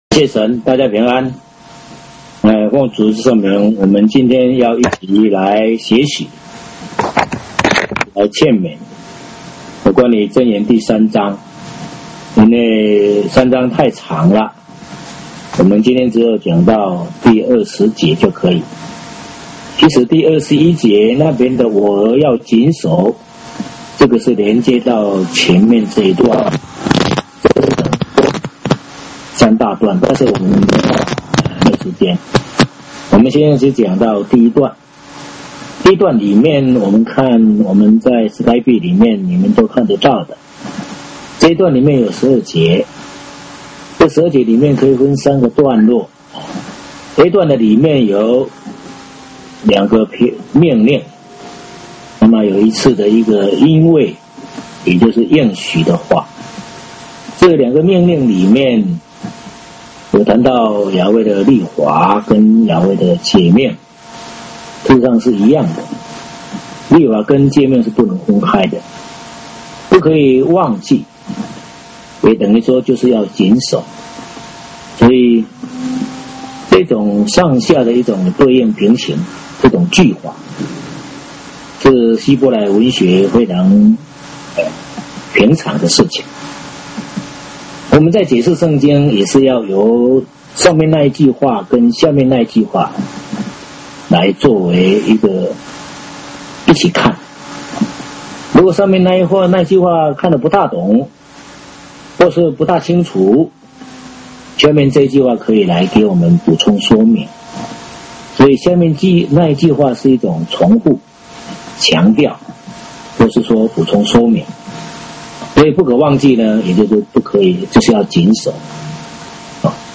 《箴言》3章上（箴 Pro 3:1-12） 第三講 [雅威聖會 聖經真理研究院]